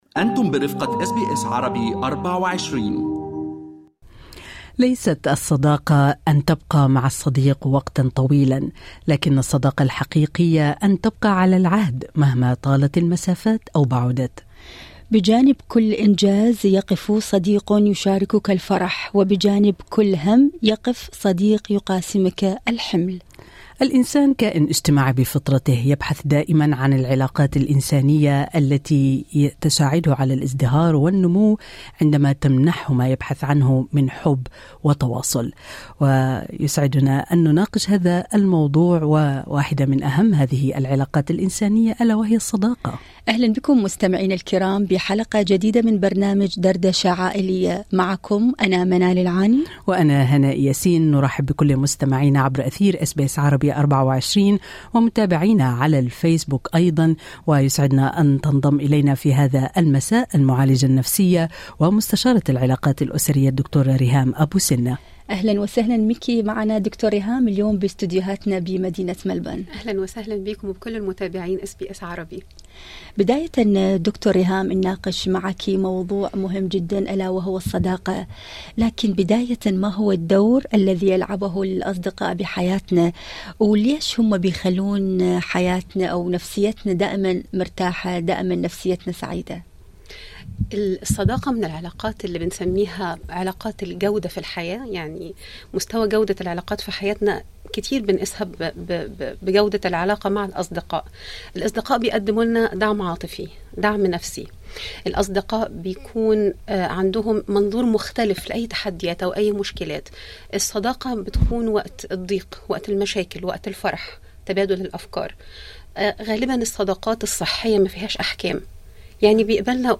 دردشة عائلية